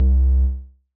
KIN Bass C1.wav